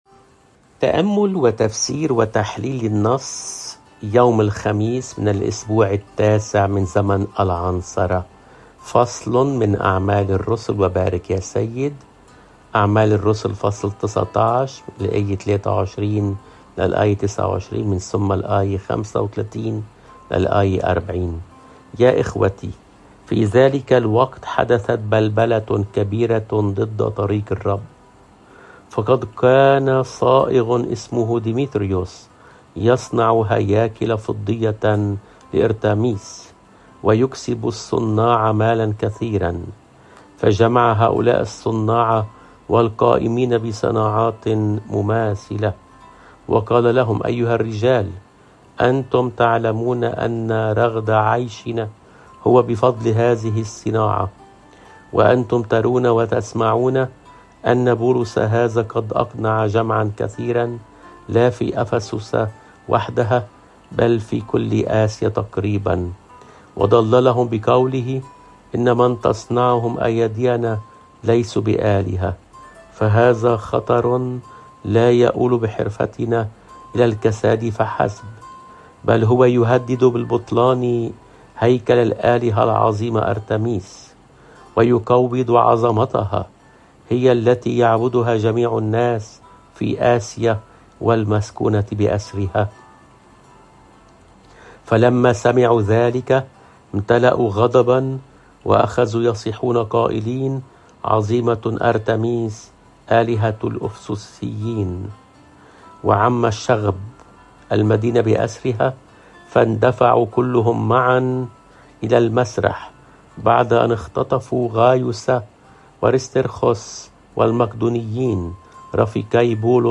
الرسالة